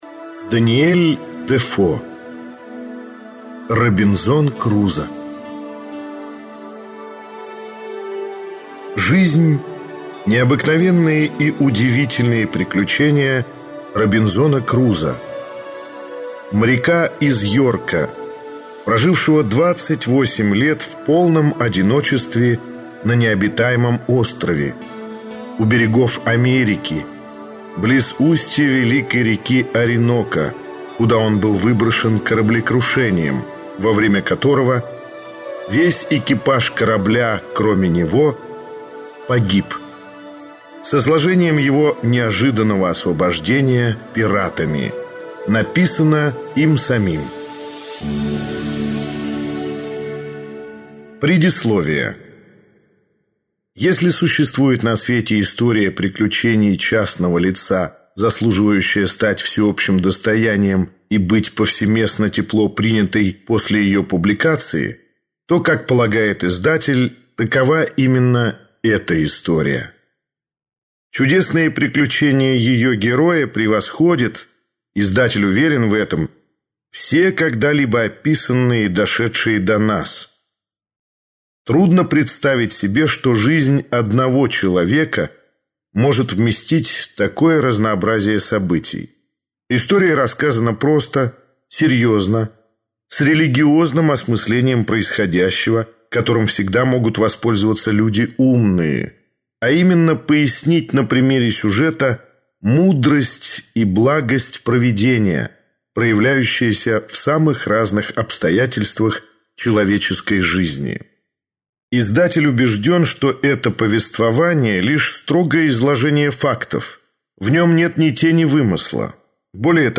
Аудиокнига Робинзон Крузо - купить, скачать и слушать онлайн | КнигоПоиск